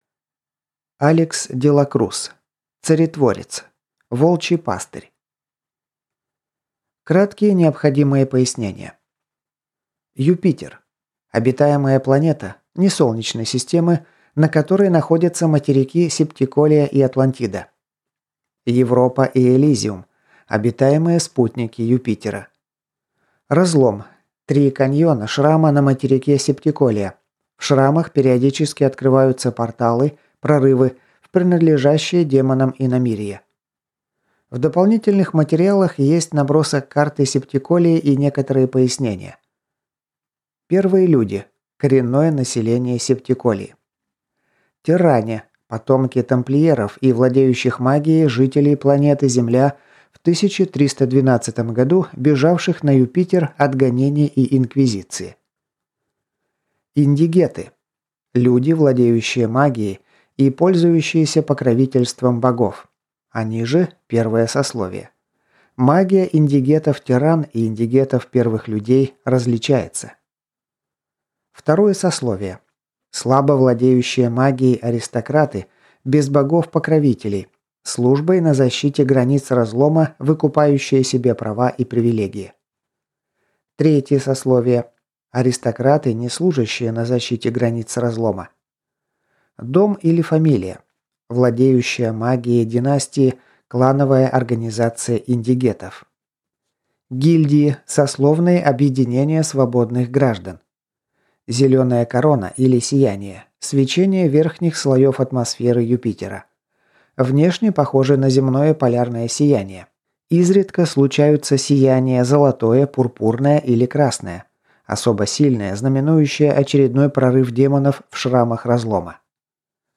Аудиокнига Царетворец. Волчий пастырь | Библиотека аудиокниг